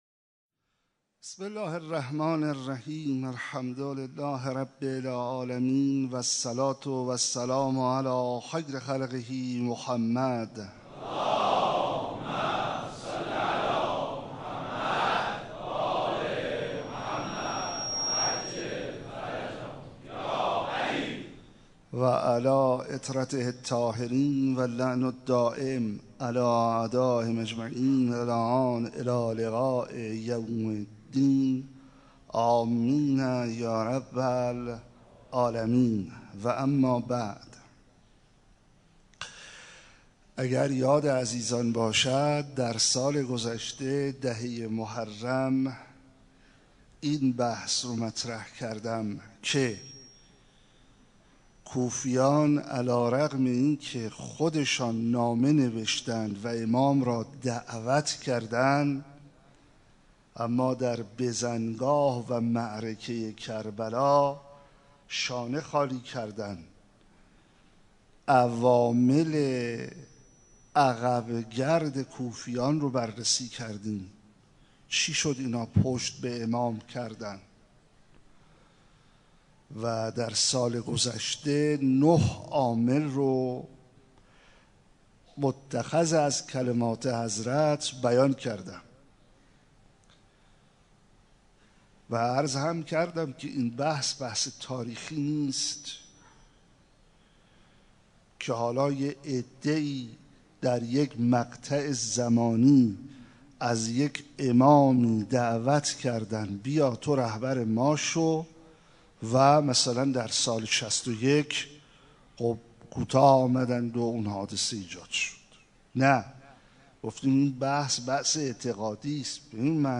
مسجد حضرت امیر
سخنرانی